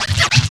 108 SCRTCH-L.wav